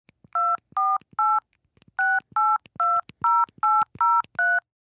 Звуки гудков телефона
Звучание цифр в тональном режиме